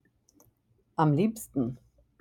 am liebsten (am LIEB-sten)